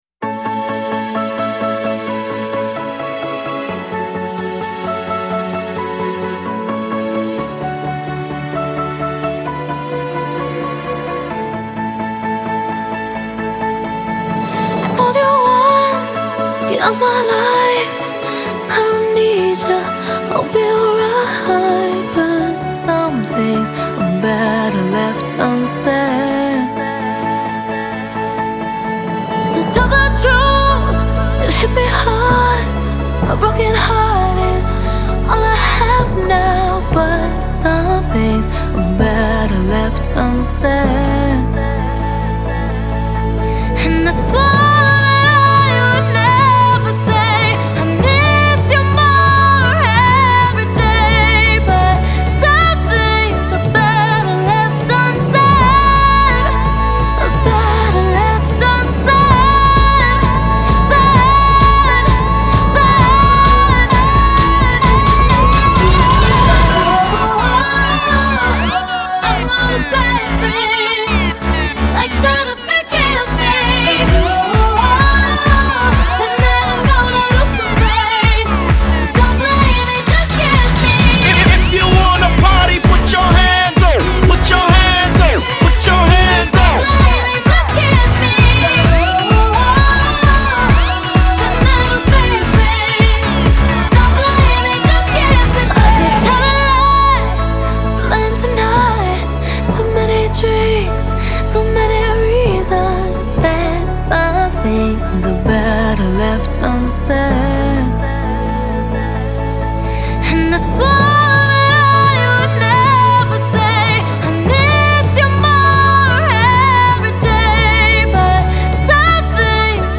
Genre : Pop